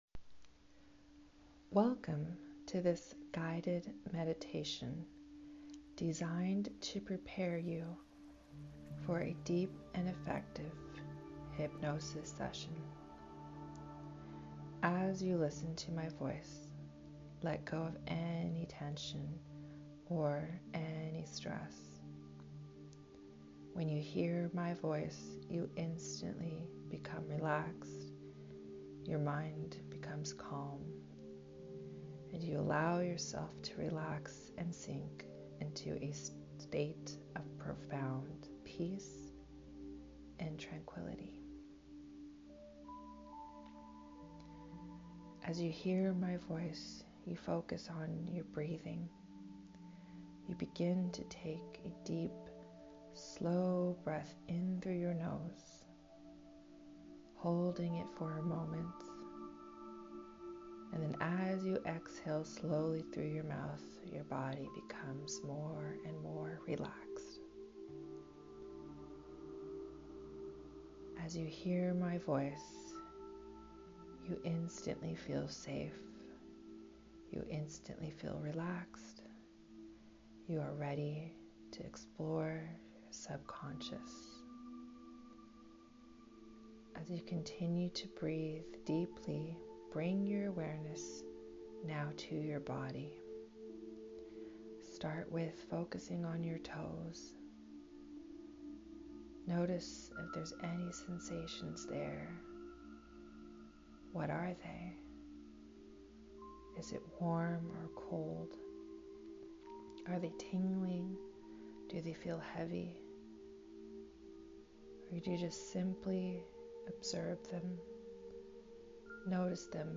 Regardless if you have meditated before, listen to this guided meditation several times before our first session.